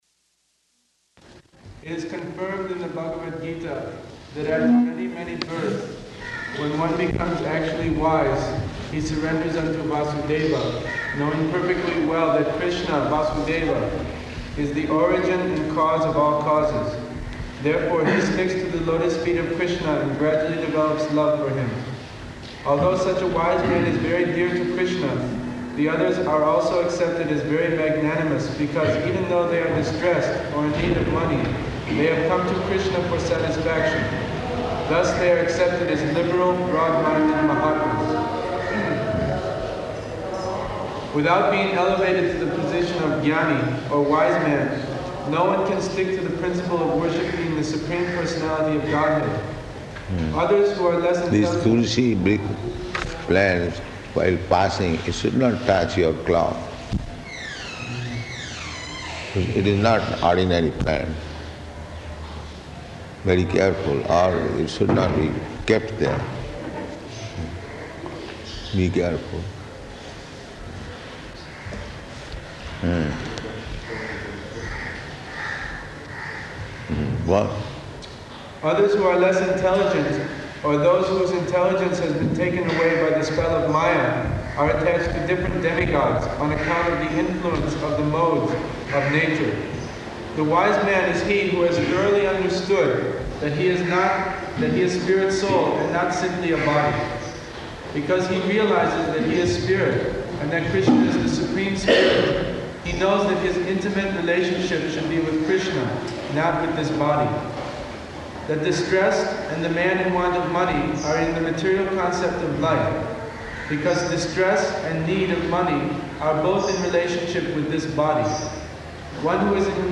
Location: Calcutta